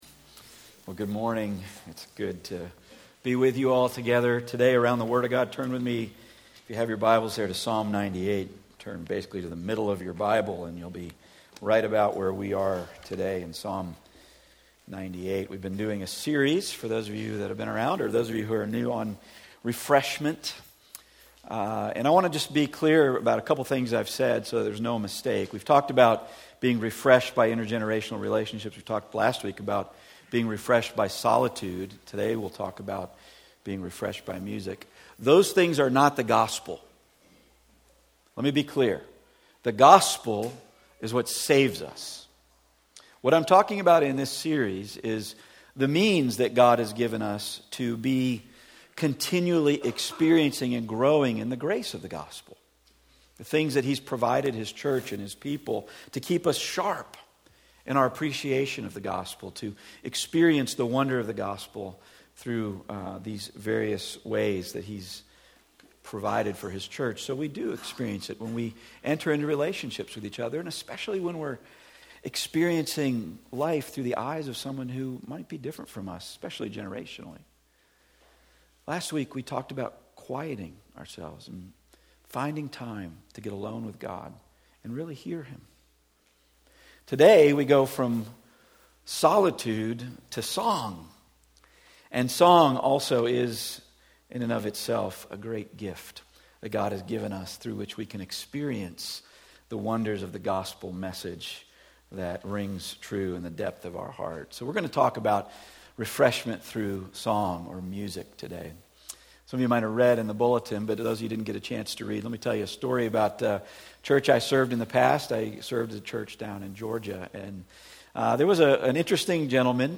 Service Type: Weekly Sunday